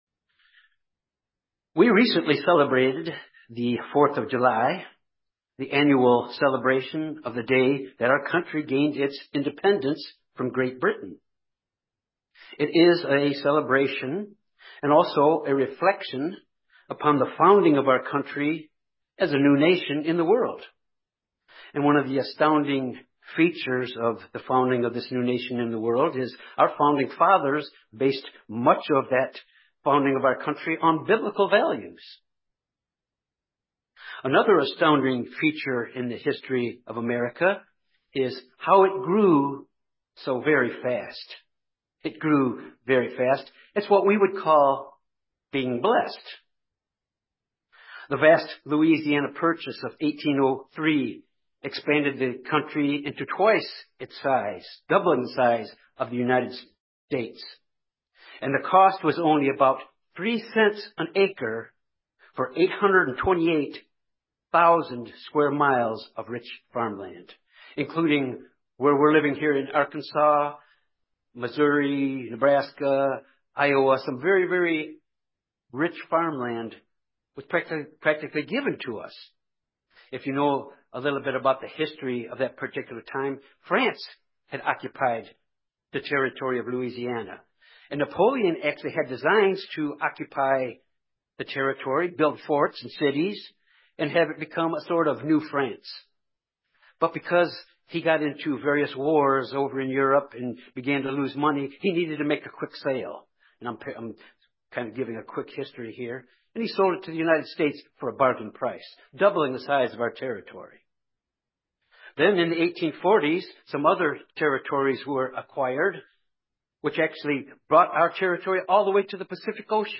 There is a cause and an effect principle of prophecy. This sermon examines the cause and effect principle of Bible prophecy as it relates to the spiritual state of our nation.